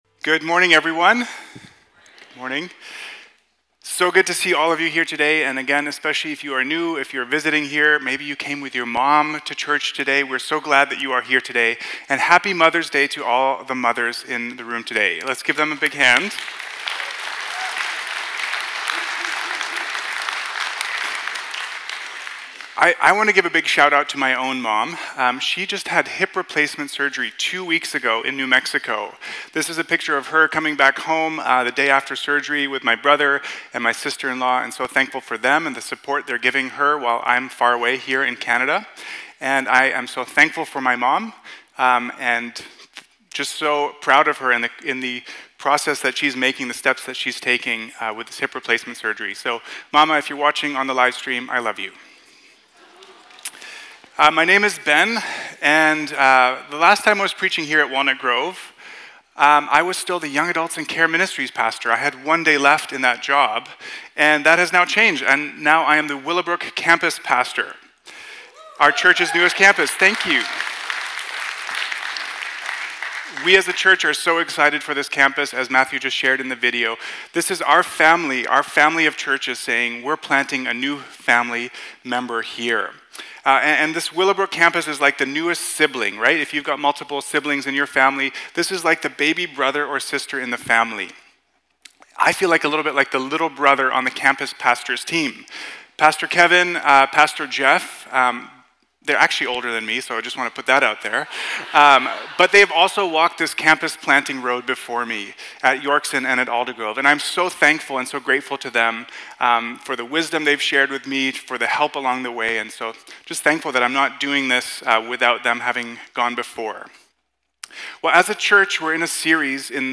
Walnut Grove Sermons | North Langley Community Church